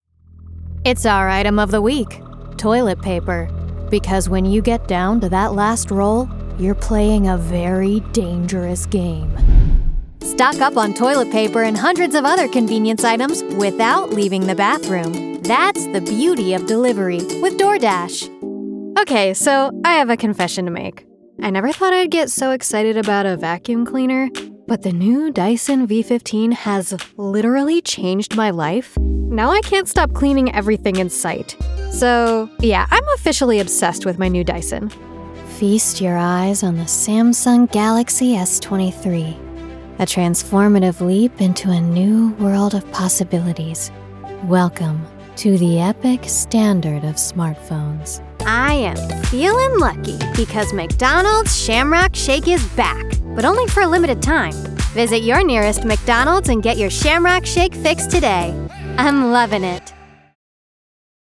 Detalhado
Moderno
Determinado